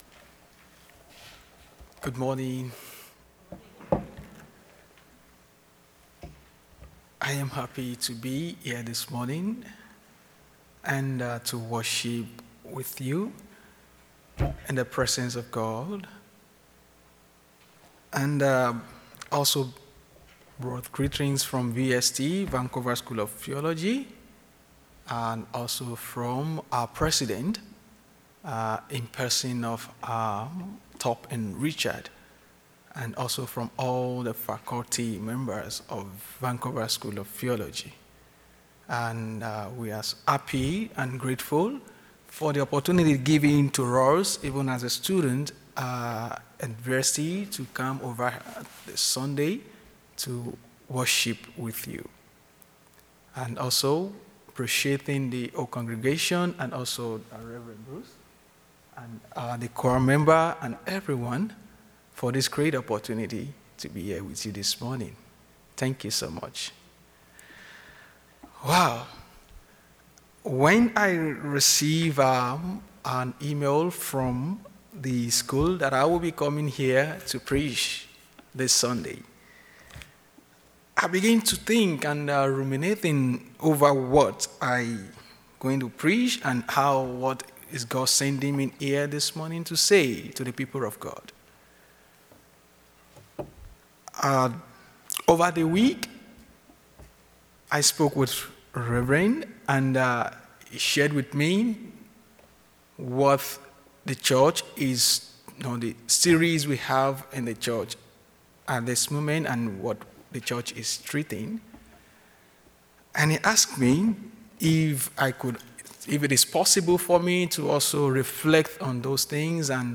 Sermon
Guest Speaker